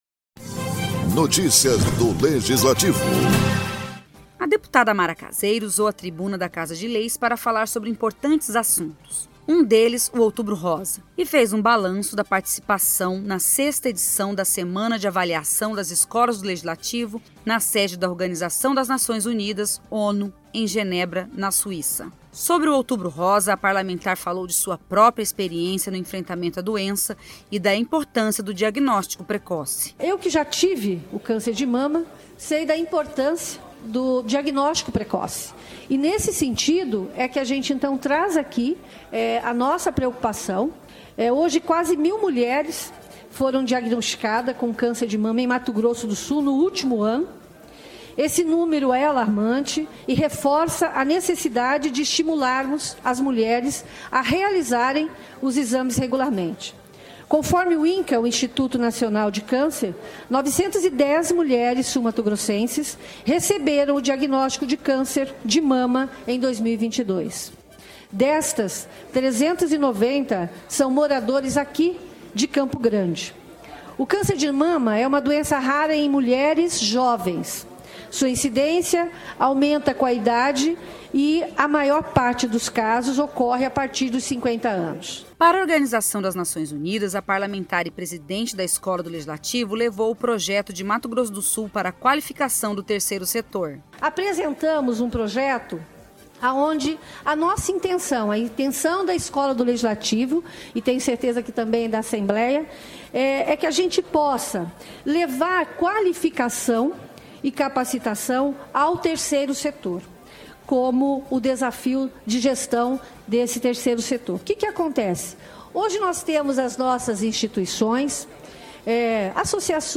A Deputada Mara Caseiro usou a Tribuna da Casa de Leis para falar sobre dois importantes assuntos, um deles o Outubro Rosa e fez um balanço da participação Escola do Legislativo Senador Ramez Tebet na 6ª edição da Semana de Avaliação das Escolas do Legislativo